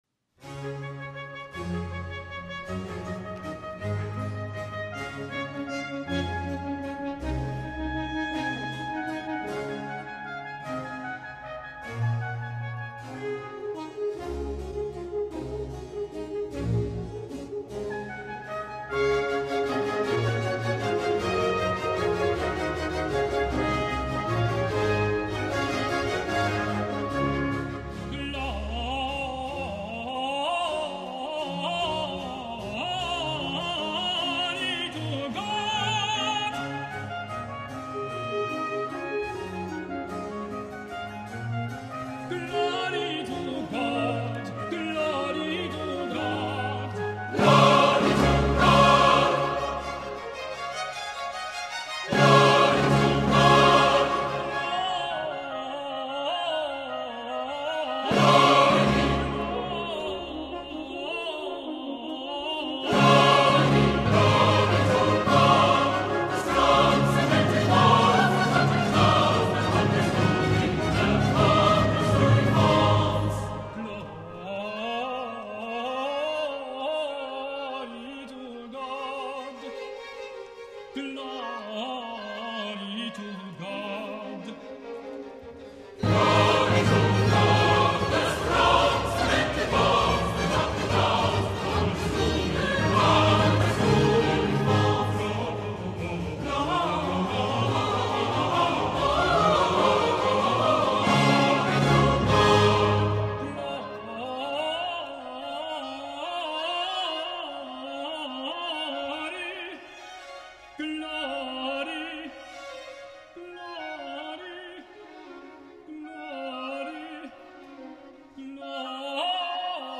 Er legt vor allem Wert darauf, so viel wie möglich von der ganz besonderen Aura der Konzerte zu vermitteln, was ausgezeichnet gelingt.
Chorus from Messiah ~ CD II, No. 16